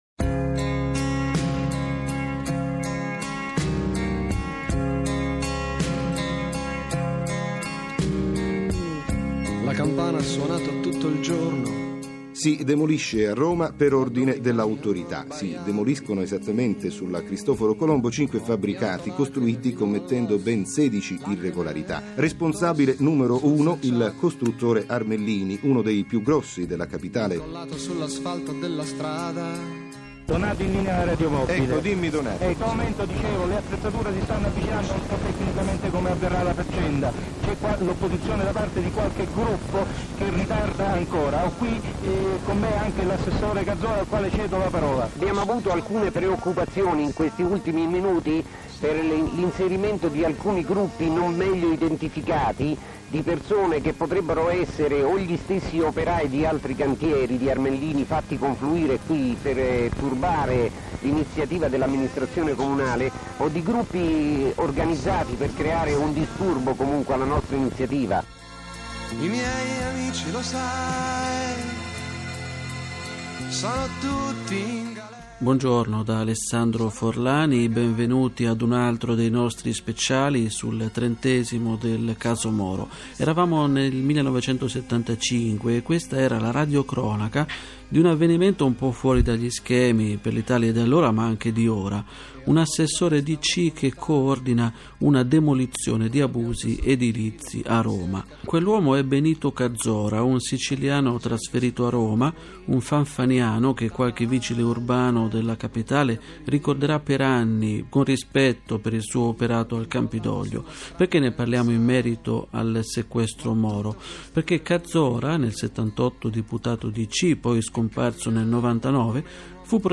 Una intervista